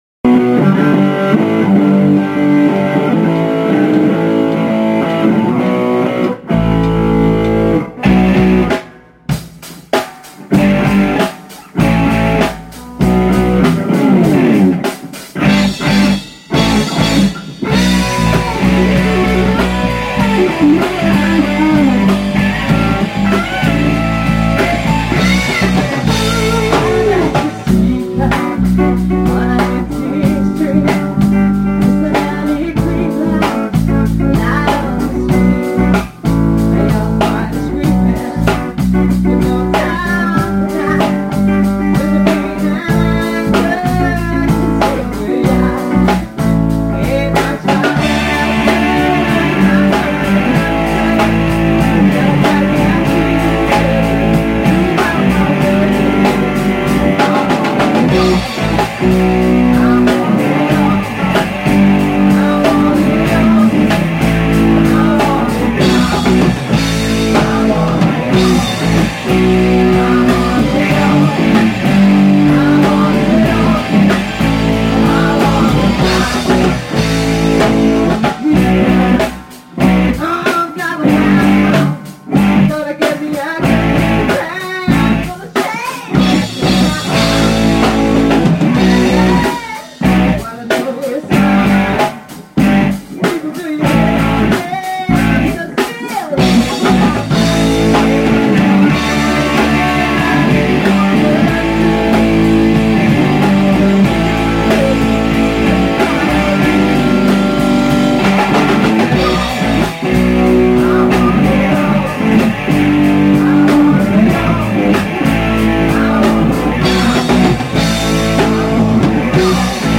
LIVE OG ØVEREN
Sang
Guitarer